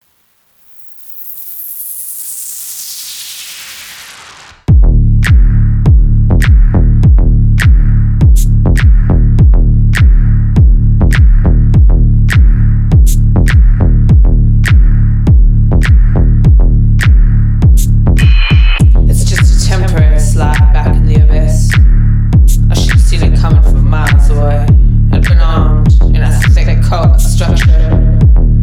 • Качество: 320, Stereo
женский голос
Electronic
стильные
electro house
techno